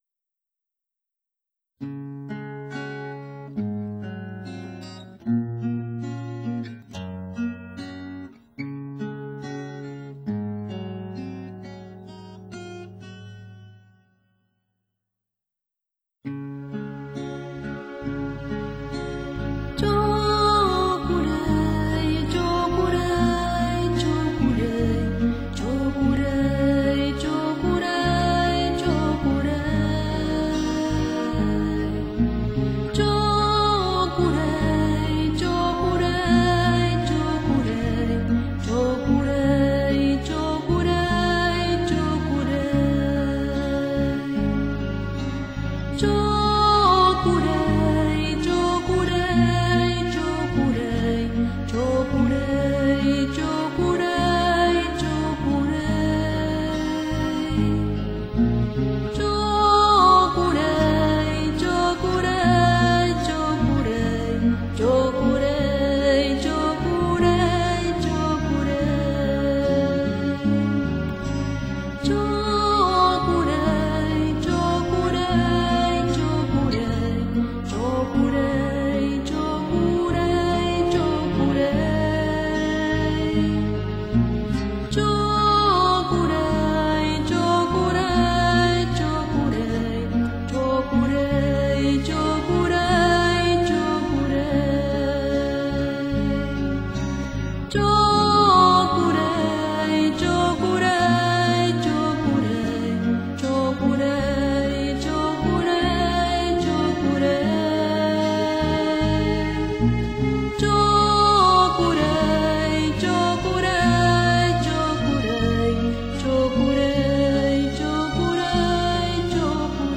CHANTS REIKI